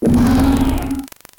Cri de Polagriffe dans Pokémon Noir et Blanc.